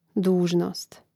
dúžnōst dužnost